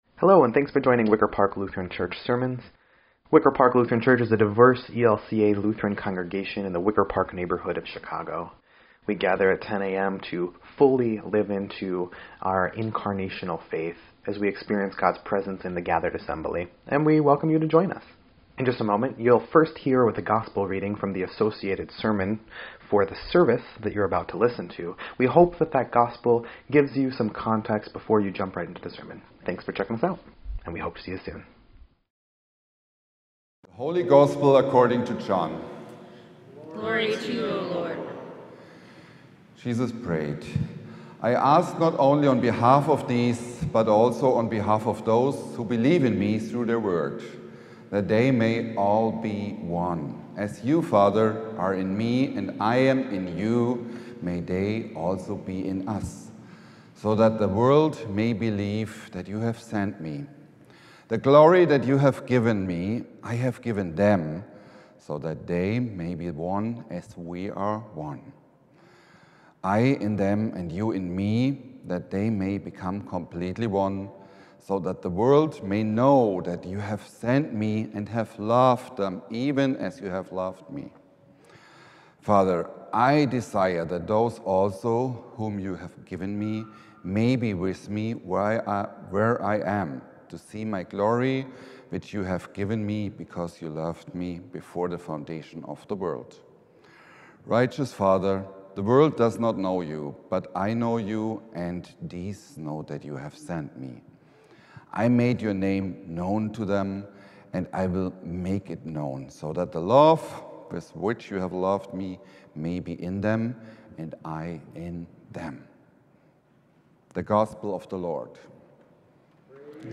6.1.25-Sermon_EDIT.mp3